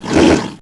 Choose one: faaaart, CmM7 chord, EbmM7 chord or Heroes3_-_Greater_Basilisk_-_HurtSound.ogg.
Heroes3_-_Greater_Basilisk_-_HurtSound.ogg